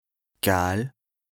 L’alsacien regroupe plusieurs variantes dialectales du Nord au Sud de l’Alsace.
Nous avons tenté d’être représentatifs de cette diversité linguistique en proposant différentes variantes d’alsacien pour chaque lexique, à l’écrit et à l’oral.